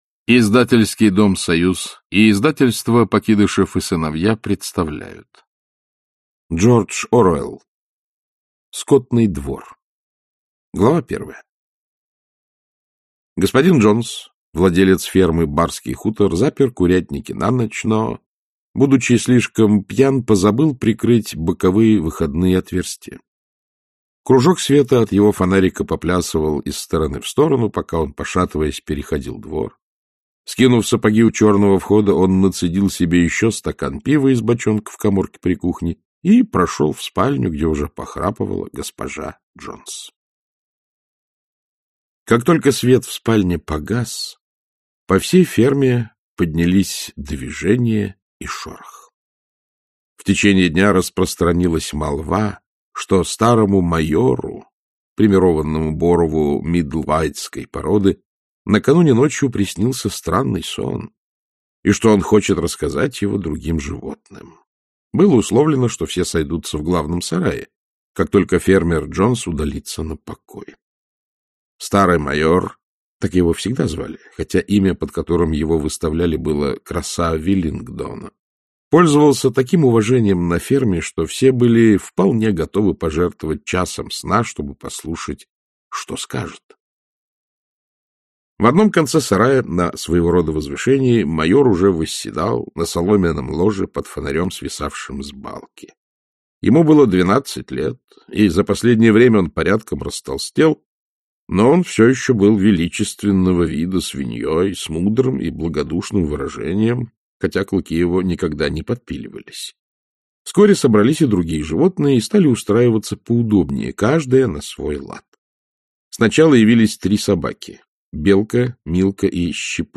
Аудиокнига Скотный двор | Библиотека аудиокниг